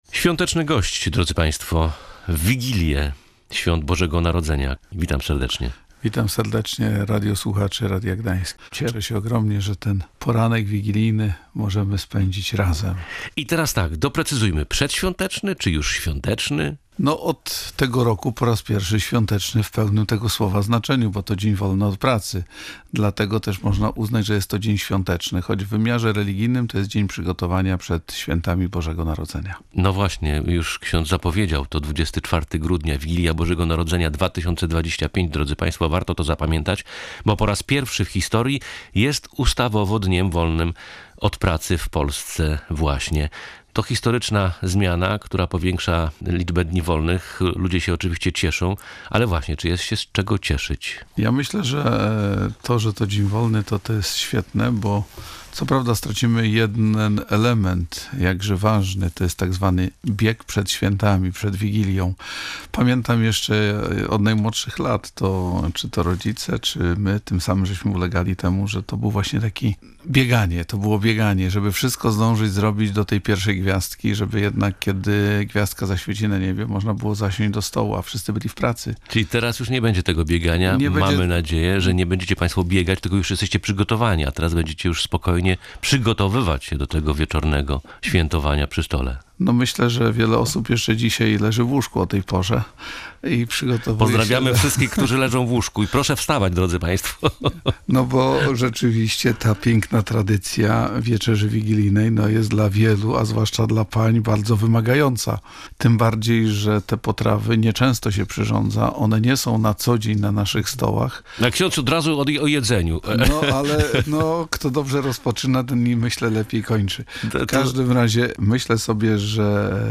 Gość Radia Gdańsk